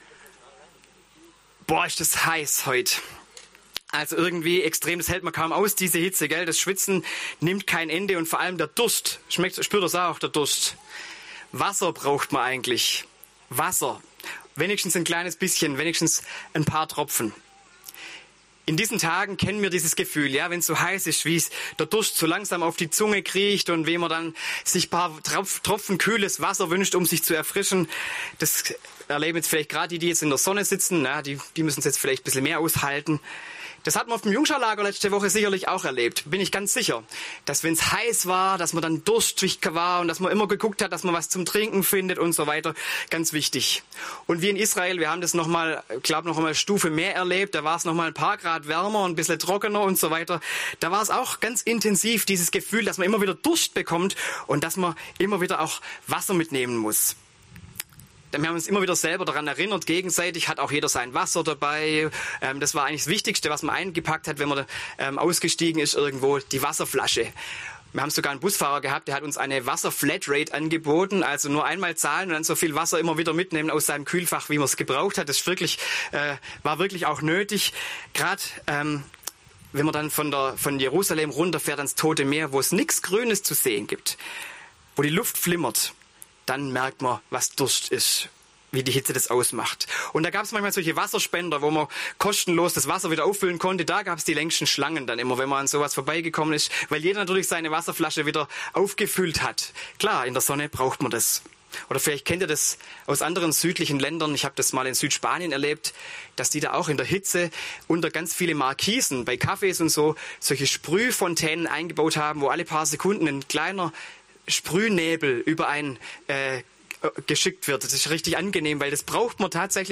Predigt
Sonntag nach Trinitatis auf der Burgruine Hohenstein.